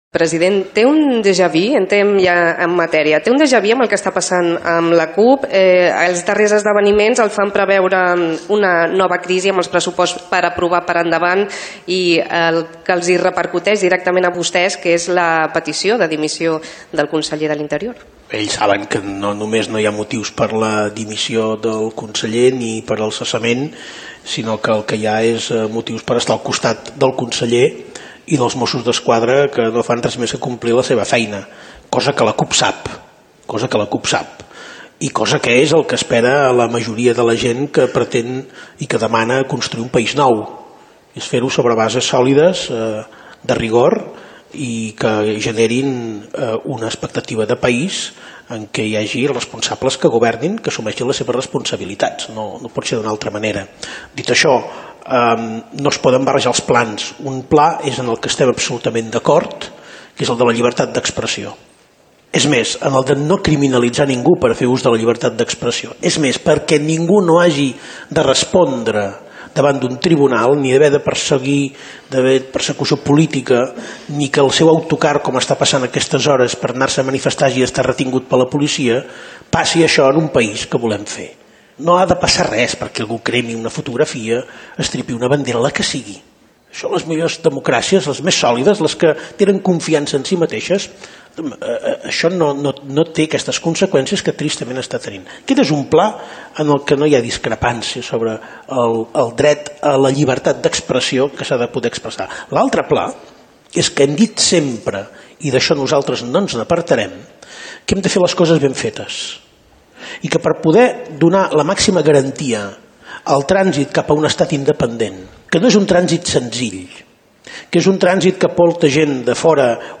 40 anys de Ràdio 4: entrevista president Carles Puigdemont - Ràdio 4, 2016